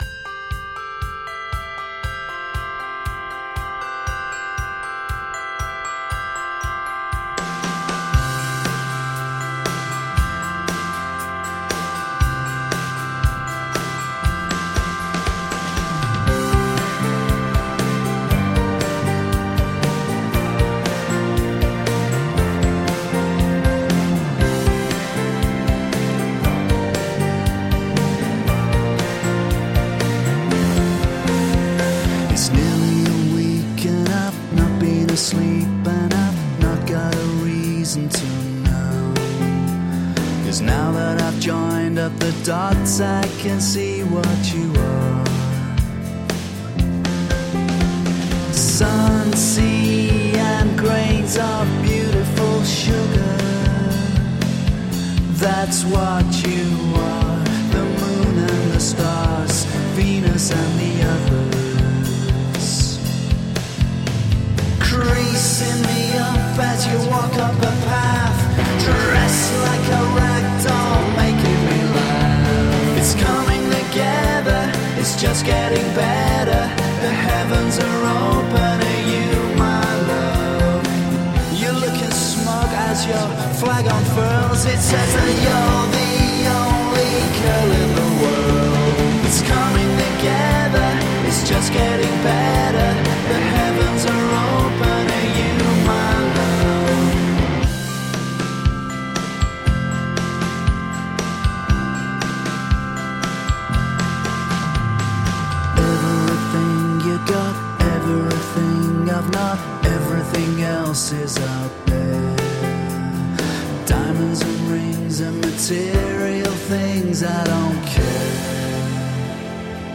Shimmering indie guitar pop with orchestral moments.